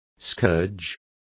Complete with pronunciation of the translation of scourged.